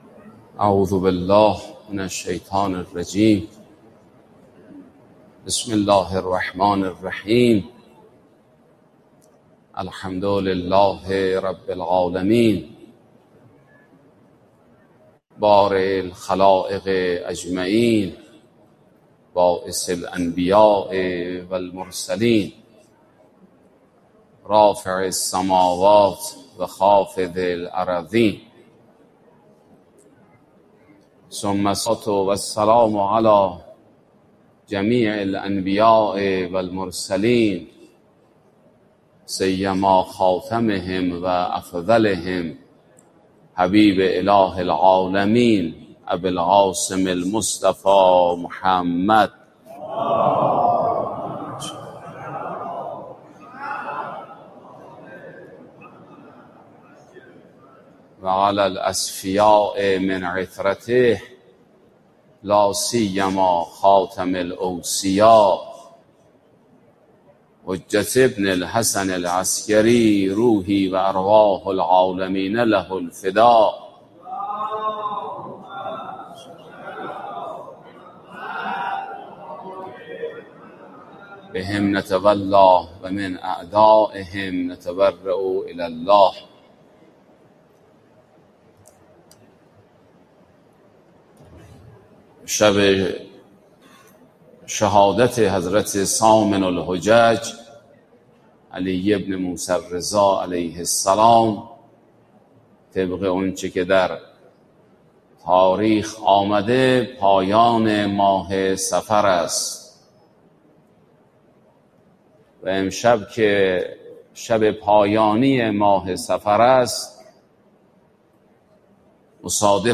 سخنرانی
مراسم سوگواری ایام آخر دهه صفر
در مسجد امام حسن عسکری علیه السلام تهران برگزار گردید.